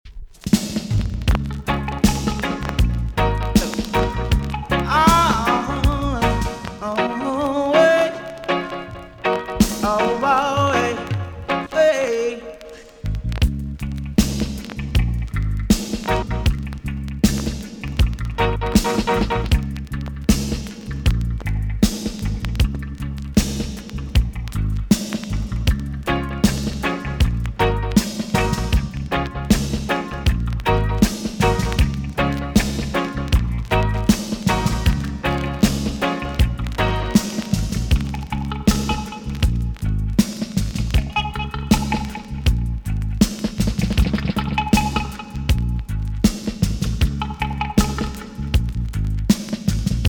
TOP >80'S 90'S DANCEHALL
B.SIDE Version
EX-~VG+ 少し軽いチリノイズがありますが良好です。